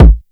Kick (8).wav